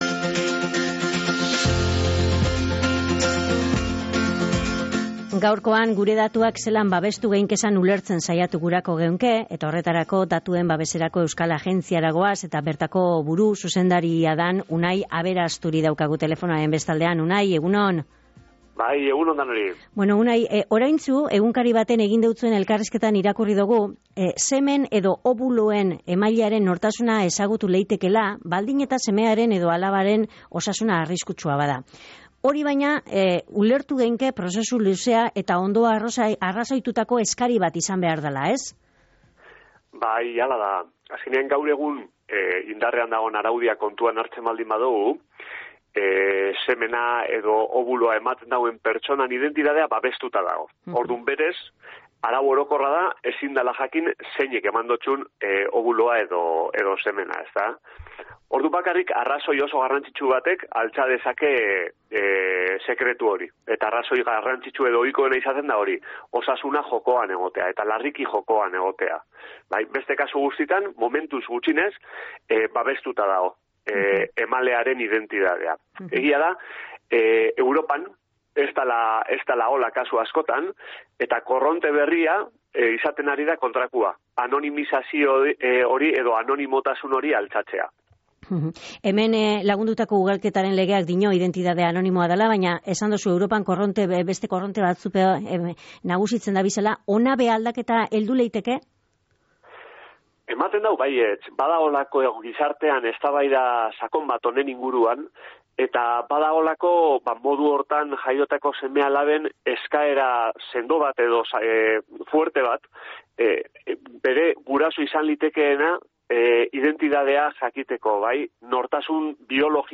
Osakidetzagaz lotutakoak dira Datuen Babeserako Euskal Agintaritzea jasoten dabezan erreklamazino ohikoenak. “Osasun datuak babestu nahi izaten doguz” esan dau Unai Aberasturik, Datuen Babeserako Euskal Agintaritzako buruak gaur Goizeko Izarretan saioan.